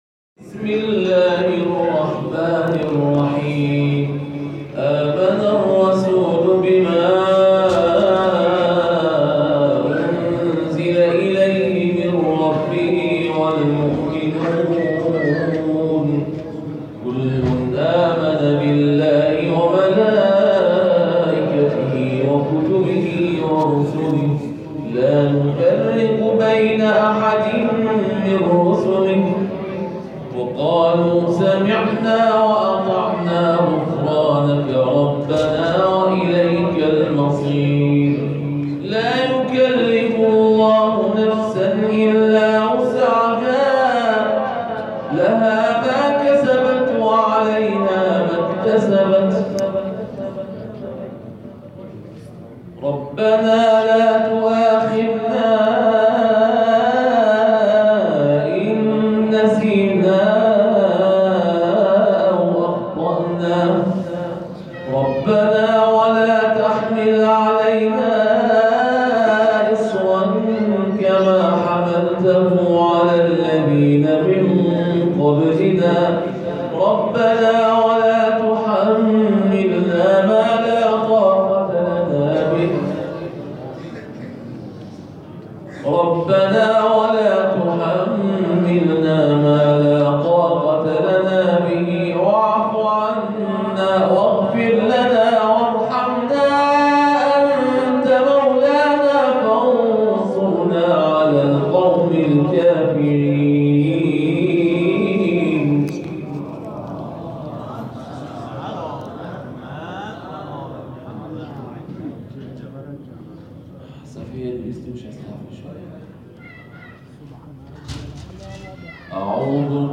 تلاوت حافظ بین‌المللی روشندل کشورمان از آیات 285 و 286 سوره بقره و همچنین آیات 91 تا 99 سوره مبارکه حجر و آیات اول تا ششم سوره مبارکه نحل